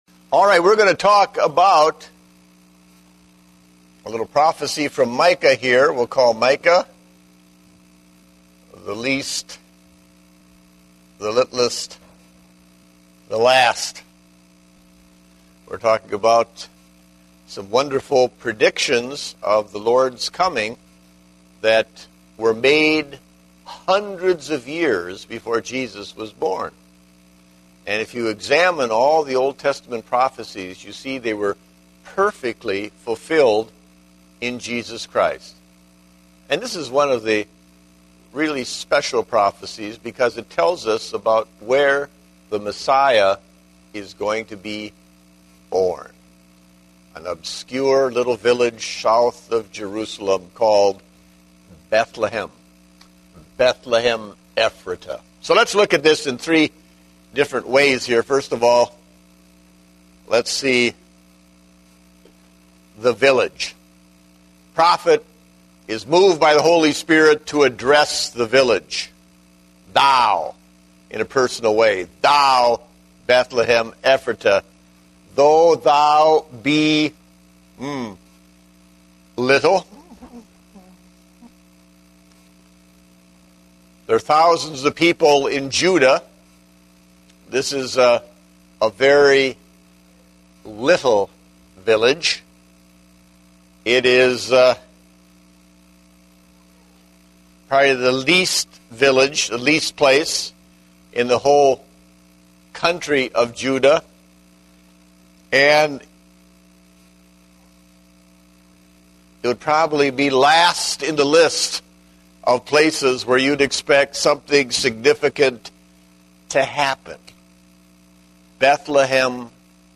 Date: December 5, 2010 (Adult Sunday School)